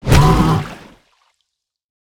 File:Sfx creature pinnacarid flinch swim 02.ogg - Subnautica Wiki
Sfx_creature_pinnacarid_flinch_swim_02.ogg